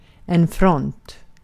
Uttal
Synonymer stridslinje frontzon Uttal Okänd accent: IPA: /frɔnt/ Ordet hittades på dessa språk: svenska Ingen översättning hittades i den valda målspråket.